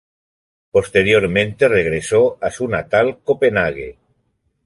/kopeˈnaɡe/